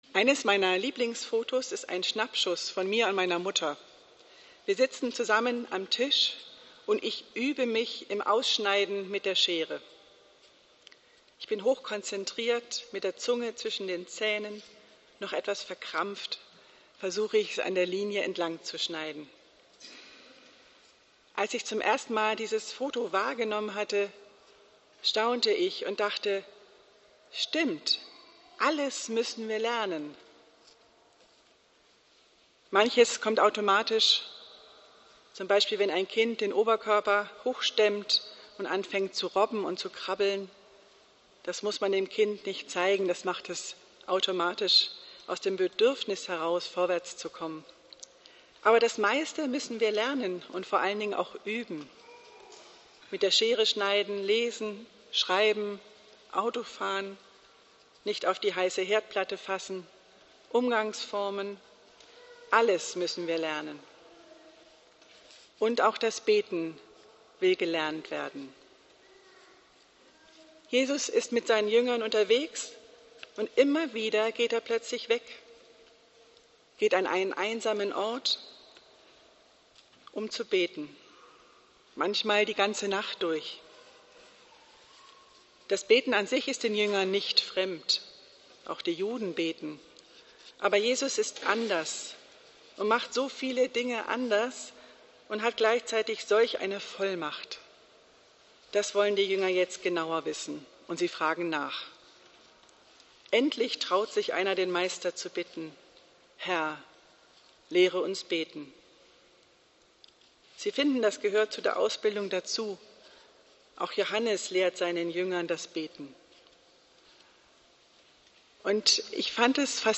Hören Sie hier die Predigt zu Lukas 11,1-4